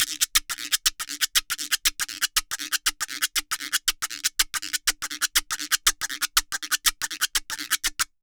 Guiro_Salsa 120_1.wav